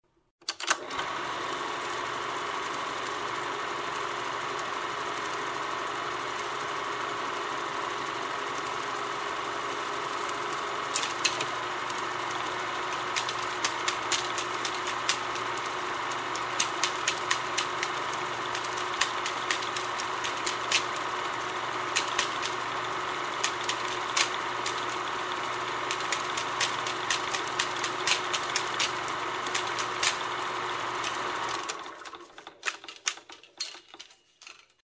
Звуки пленки
Шуршание перемотки плёнки в кинопроекторе